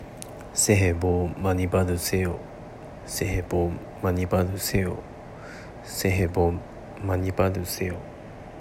セヘ　ボッ　マニバ　ドゥセヨ
■새해 복 많이 받으세요（セヘ　ボッ　マニバドゥセヨ）の発音